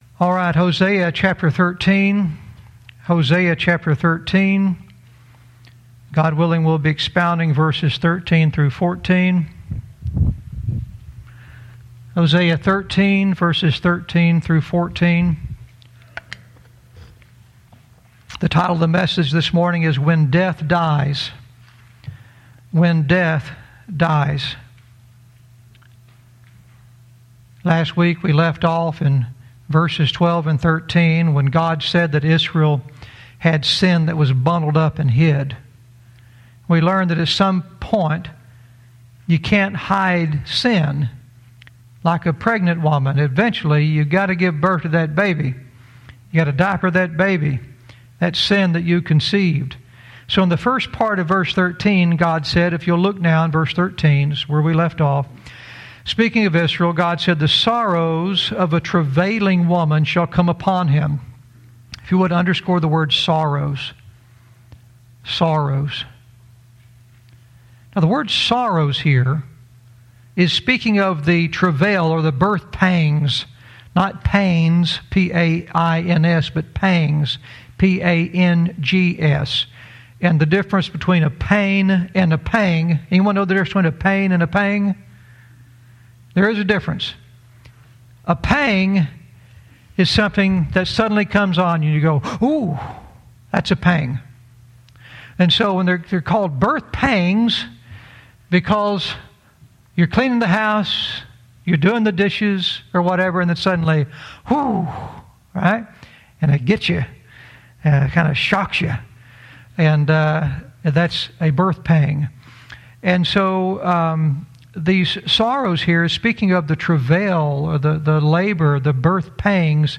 Verse by verse teaching - Hosea 13:13-14 "When Death Dies"